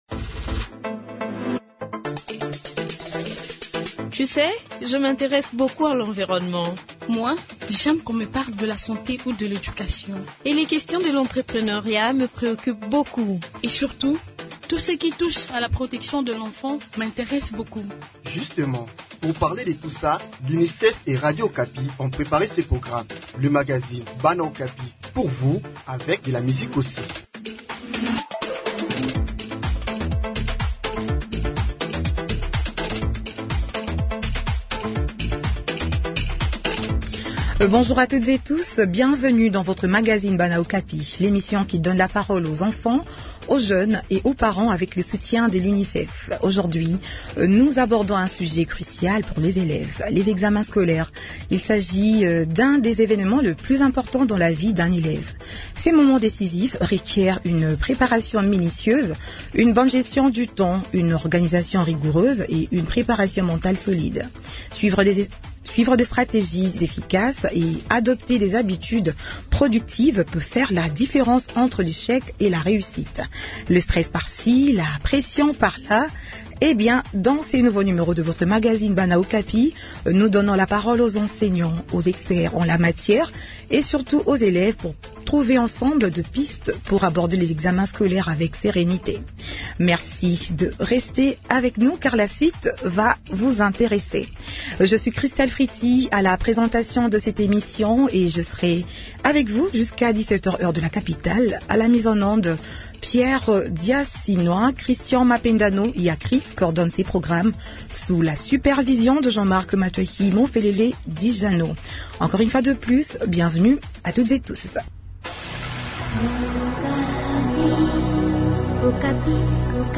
Eh bien, dans ce nouveau numéro de votre magazine Bana Okapi, nous donnons la parole aux enseignants, aux experts en la matière et surtout aux élèves pour trouver ensemble des pistes pour aborder les examens scolaires avec sérénité.